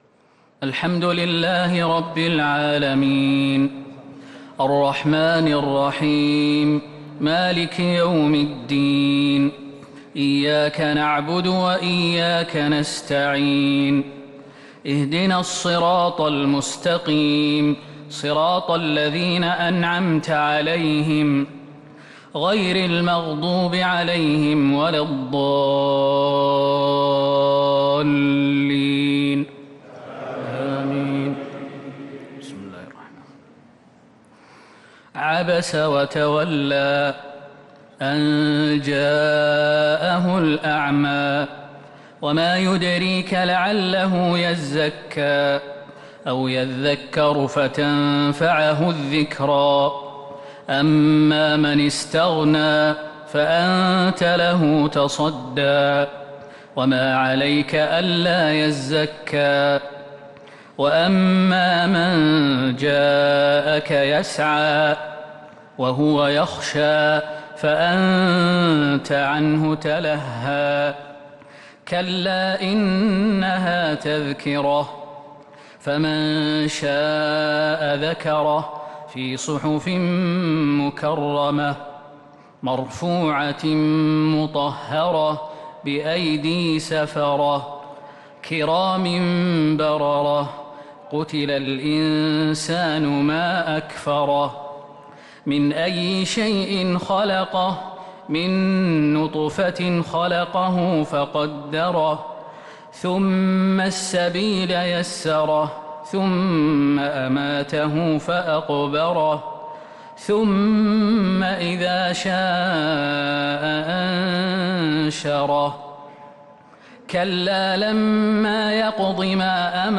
تراويح ليلة 29 رمضان 1443هـ من سورة عبس إلى سورة الشمس | taraweeh 29 st niqht Ramadan 1443H from Surah Abasa to Ash-Shams > تراويح الحرم النبوي عام 1443 🕌 > التراويح - تلاوات الحرمين